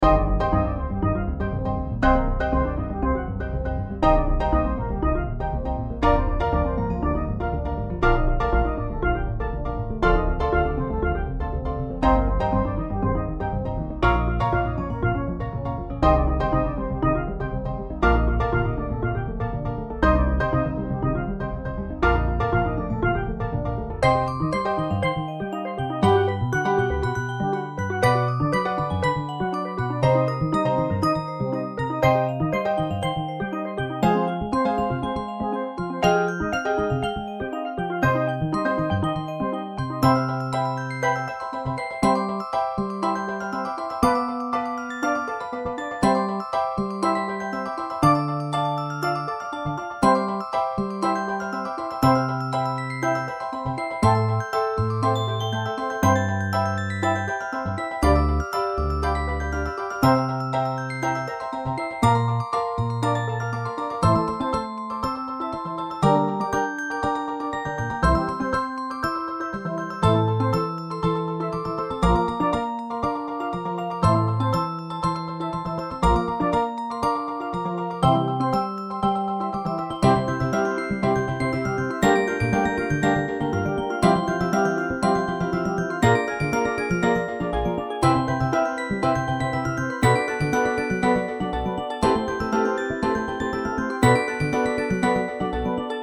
ミュージックボックス、スティールドラム、アコースティックベース、ピアノ
種類BGM